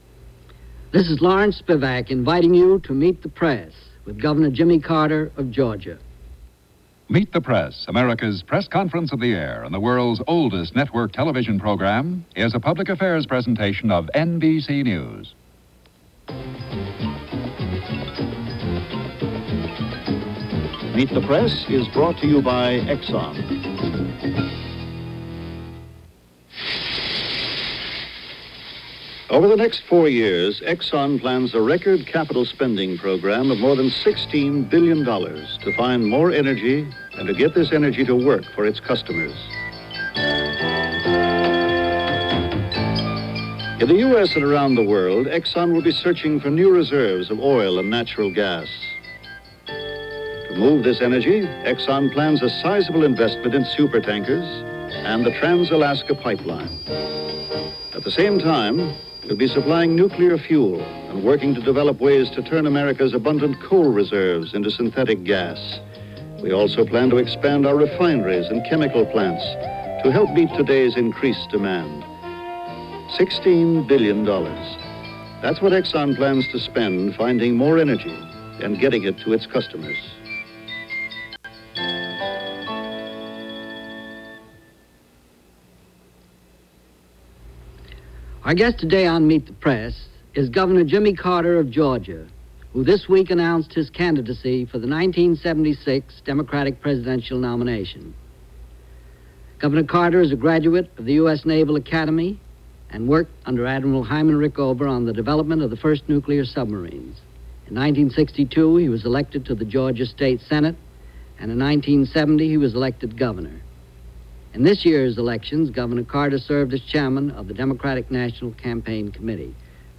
Jimmy Carter - The Prospects For 1976 - December 15, 1974 - Jimmy Carter Meets The Press.
Here is that episode of Meet The Press with guest, Governor Jimmy Carter as it was broadcast on December 15, 1974.